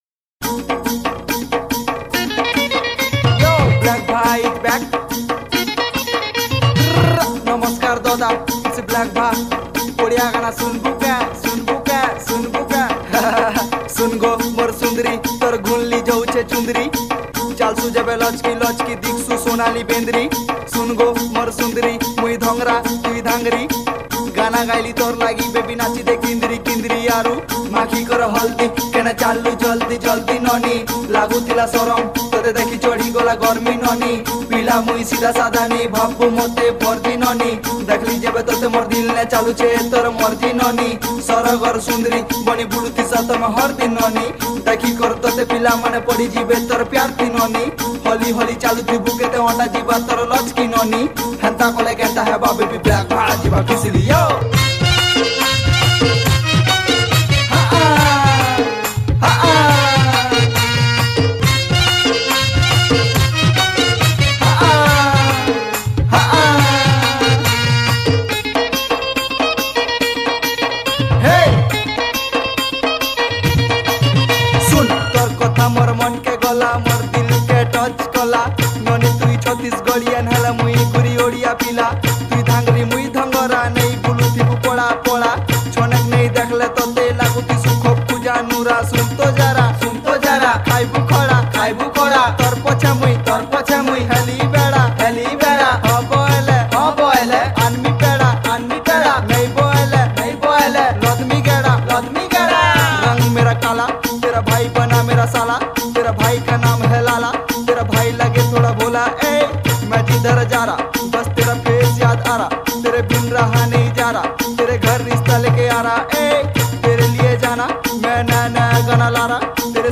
Category : Odia Rap Song